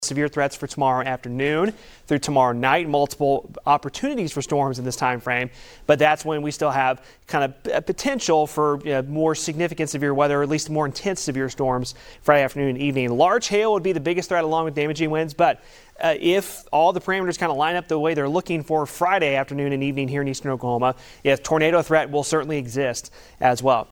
News on 6 Meteorologist